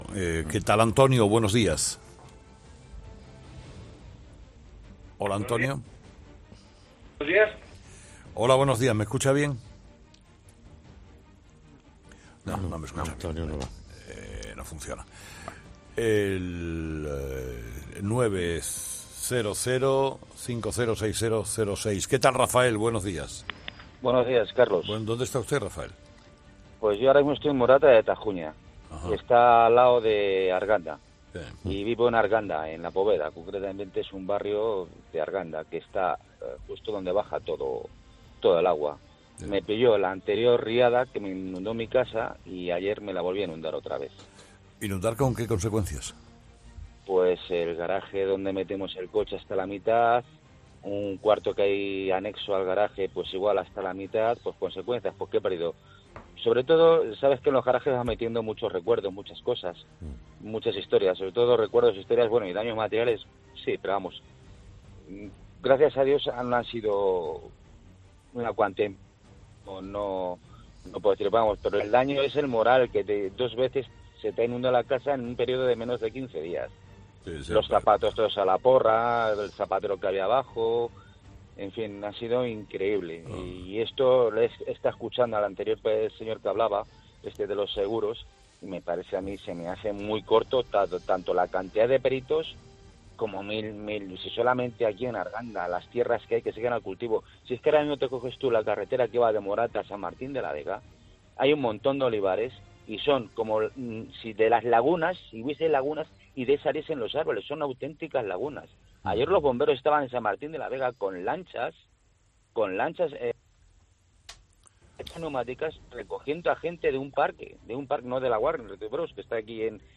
AUDIO: Este lunes los oyentes han narrado su calvario particular provocado por las inundaciones
Los fósforos es el espacio en el que Carlos Herrera habla de tú a tú con los ciudadanos, en busca de experiencias de vida y anécdotas deliciosas, que confirman el buen humor y cercanía de los españoles.